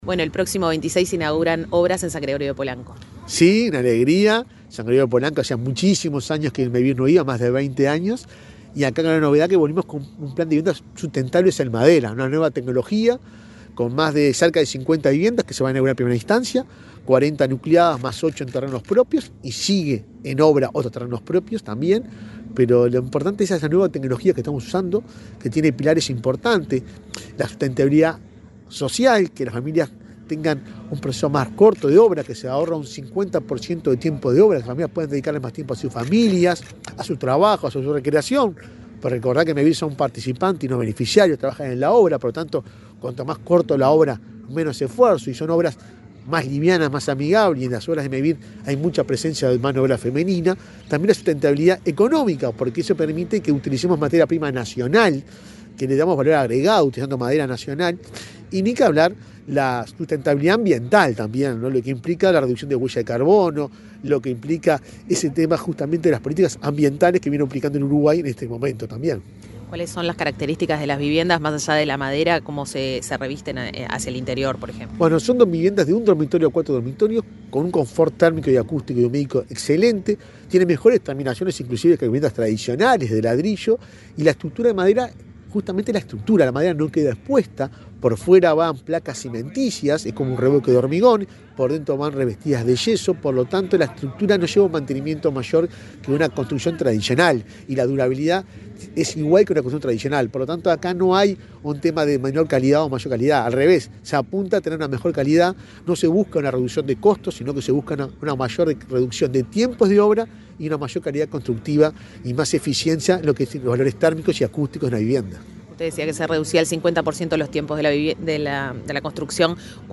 Entrevista al presidente de Mevir, Juan Pablo Delgado
El presidente de Mevir, Juan Pablo Delgado, dialogó con Comunicación Presidencial en Canelones, acerca de la próxima inauguración de viviendas en San